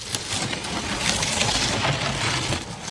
catapult.ogg